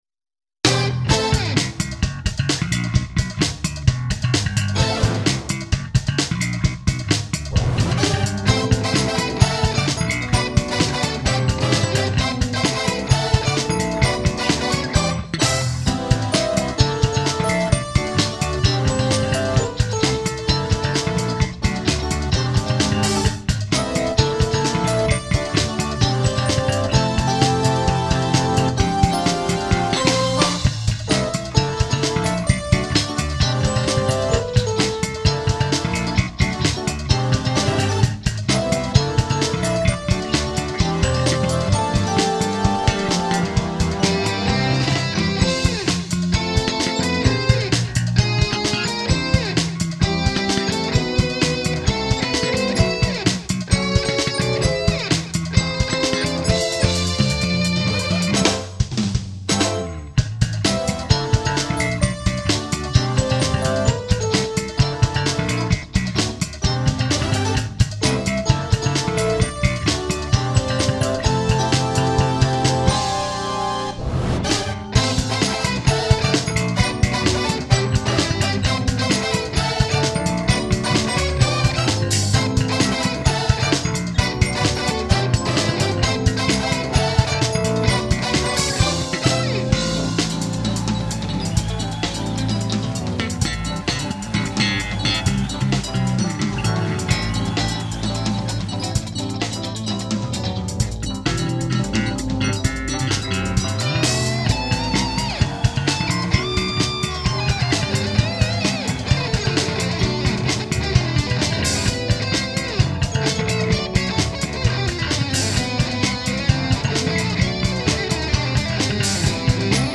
저희 밴드 연주한건데 열심히 연주하고 믹싱했습니다
2005-03-21 와~ 레코딩 잘하셨네요.